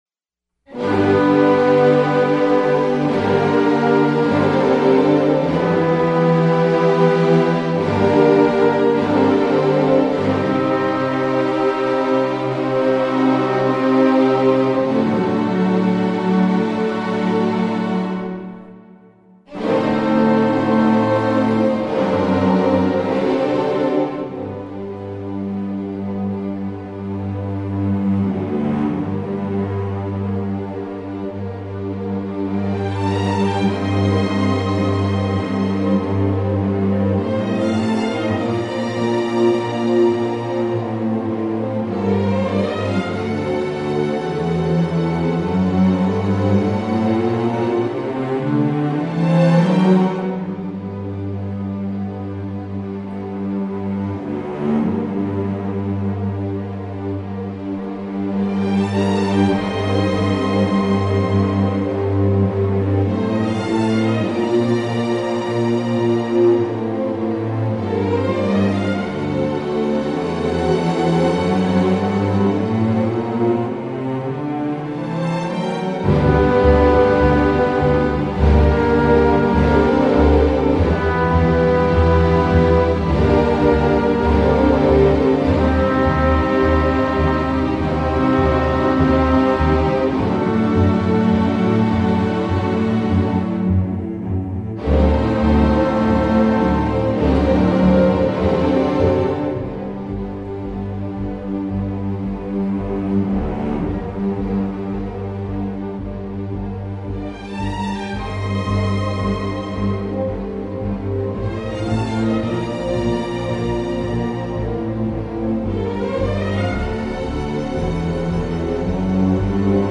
Archive for the 'instrumental' Category